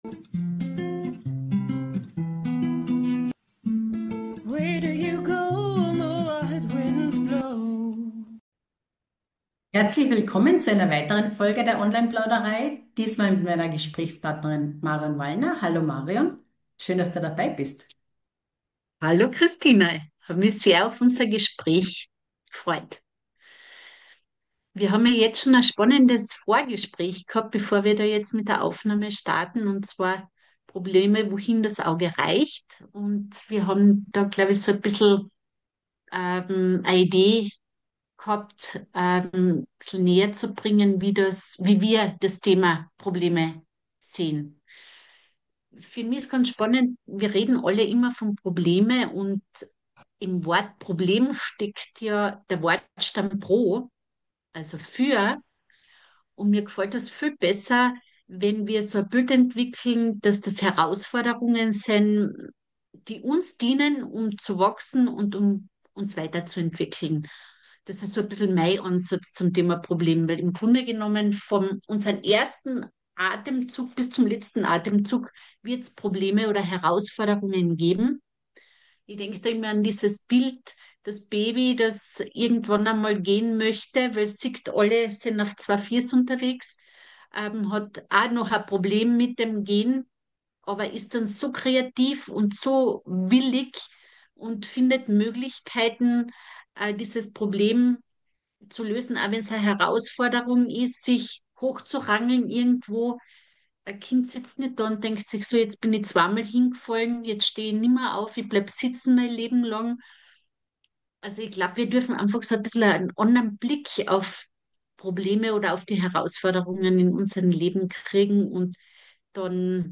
Online Plauderei – inspirierende Gespräche für persönliche Entwicklung und energetische Arbeit im Online-Zeitalter.